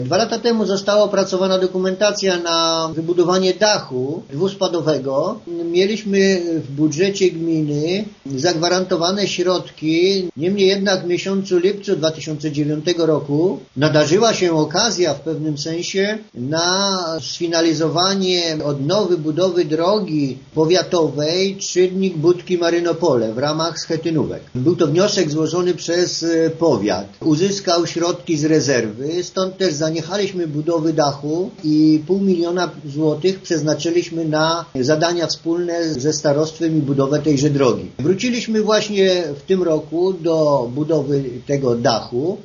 Wójt Franciszek Kwiecień przypomina, że remont miał być wykonany już w 2009 roku, ale zaplanowane na ten cel pieniądze przeznaczono wówczas na... drogę: